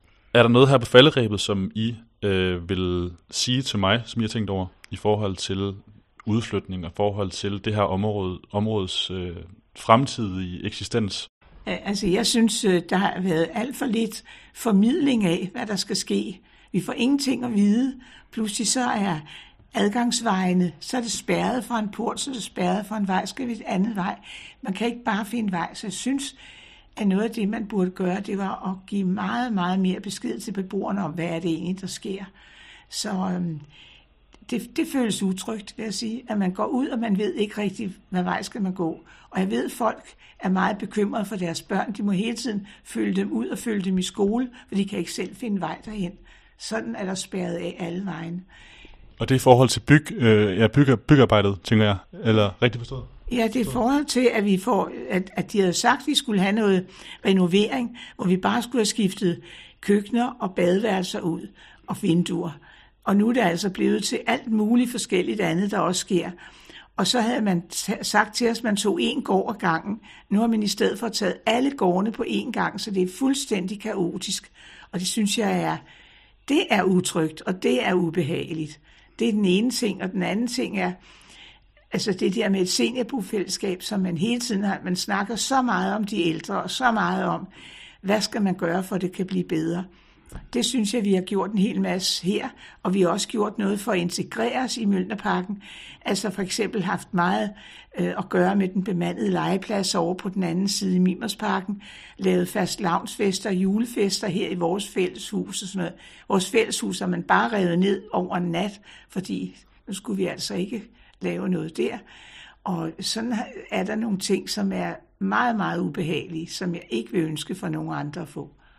Her er en lille lydbid fra et interview, jeg lavede med en af bofællesskabets tidligere beboere.